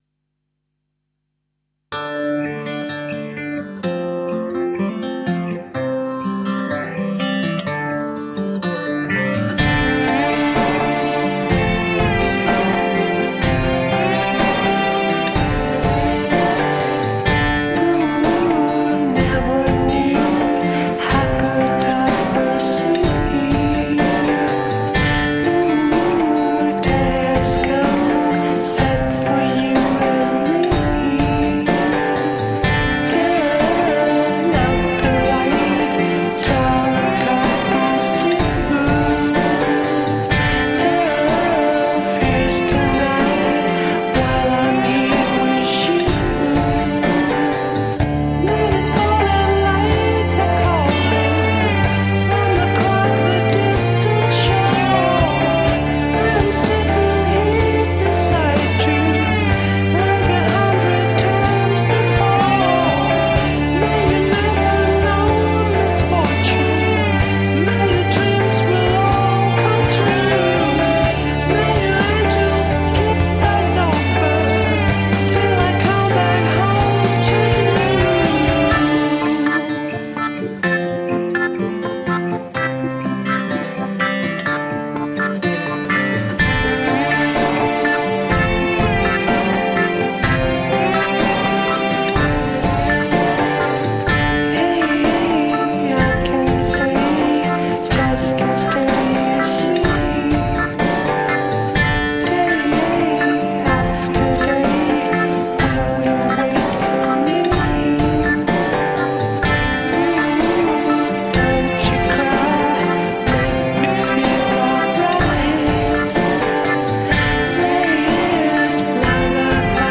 Tagged as: Alt Rock, Rock, Classic rock, Prog Rock